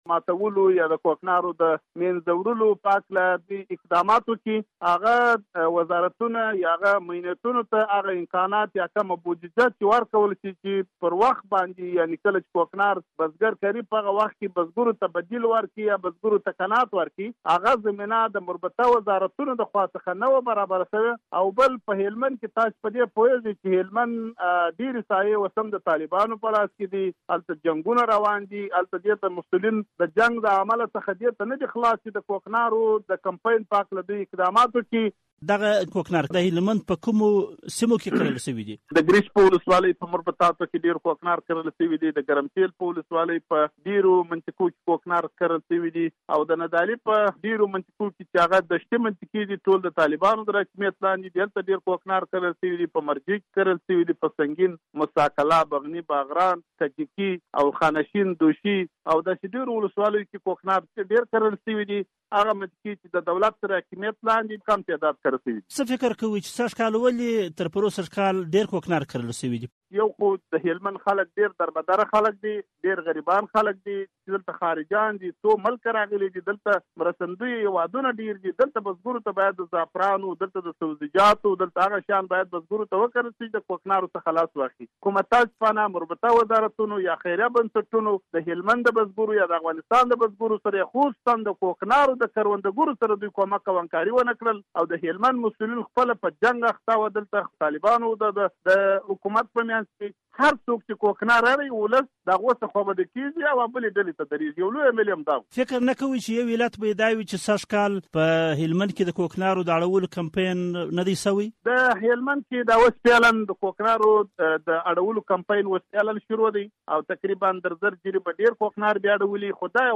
دا چې د دې کار علت څه دی؟ په دې اړه مو په مشرانو جرګه کې له نشه يي موادو سره د مبارزې کمېسيون له غړي هاشم الکوزي سره مرکه کړې او په پيل کې مو پوښتلی چې ولې سږکال په هلمند کې تر پخوا ډېر کوکنار کرل شوي دي؟
الکوزي صیب سره مرکه